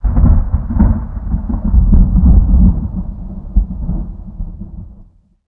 thunder34.ogg